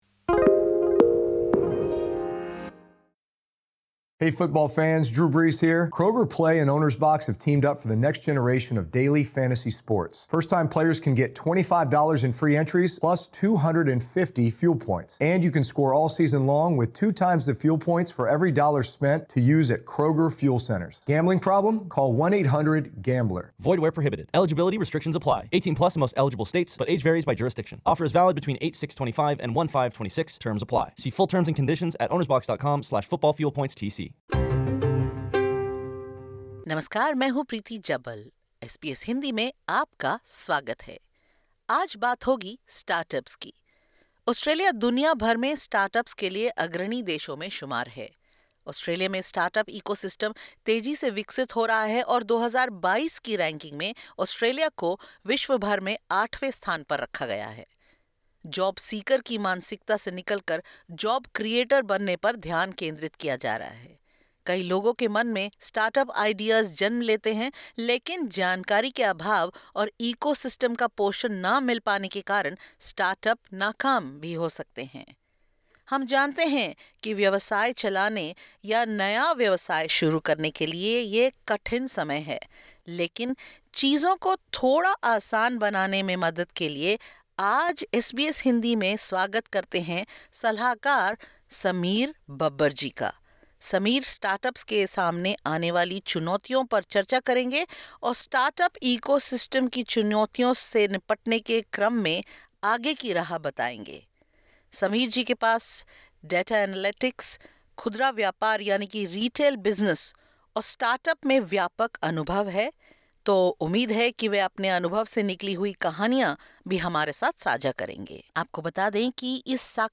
Note: We would like to inform you that the information expressed in this interview is of general nature.